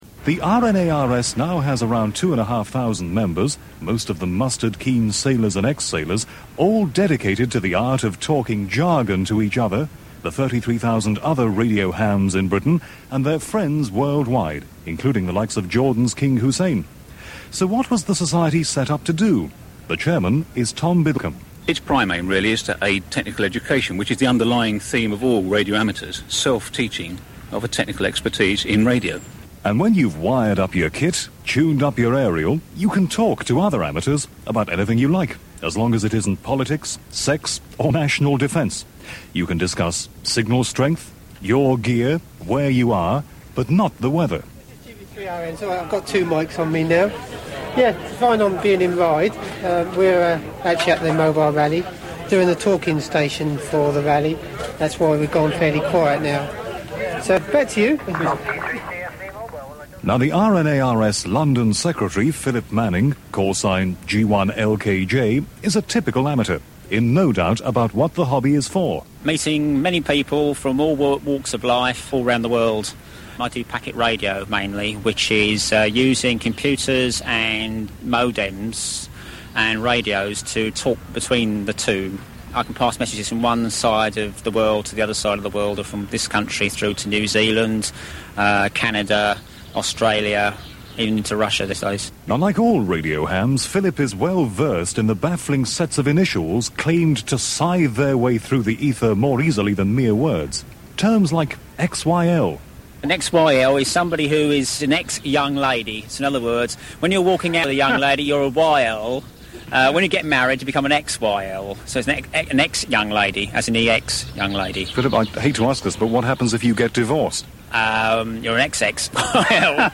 The world of radio hams and Q codes is investigated in this feature on BBC Radio 4’s The Radio Programme in 1992.